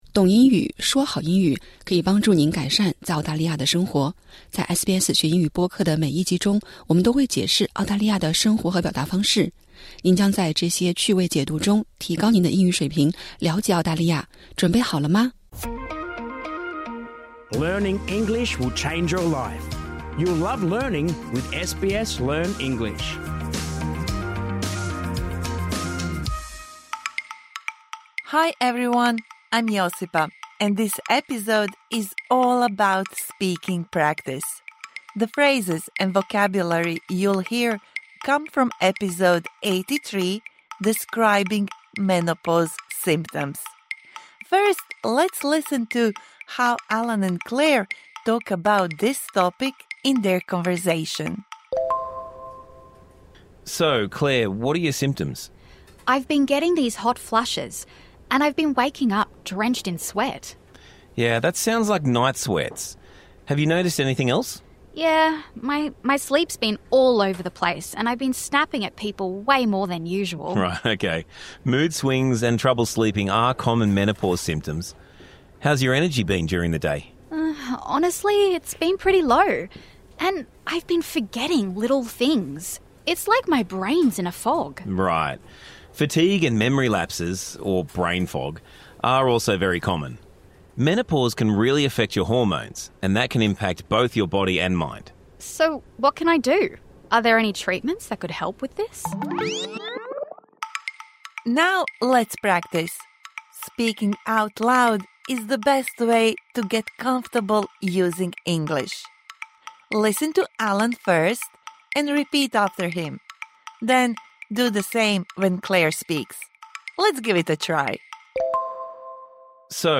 本期为《学英语》第 83 集描述更年期症状的附加集，对这一集中学到的单词和短语进行互动口语练习。